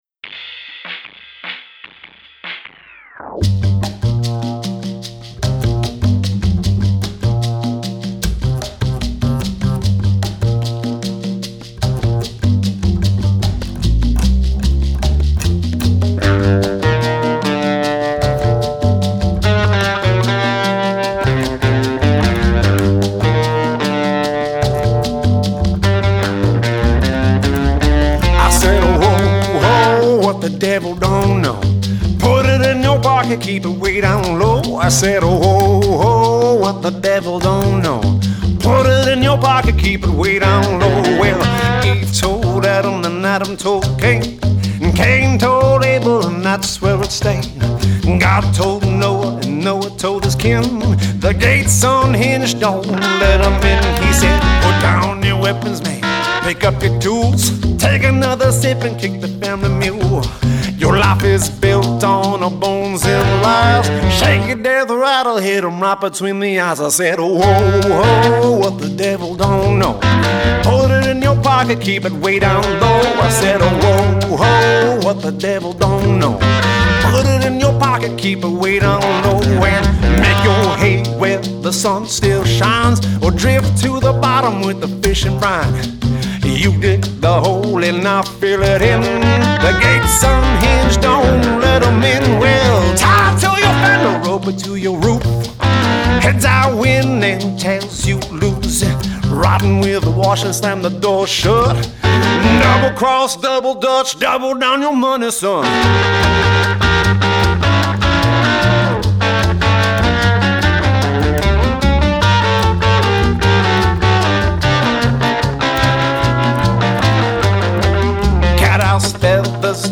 with hypnotic upright bass, beat lyricism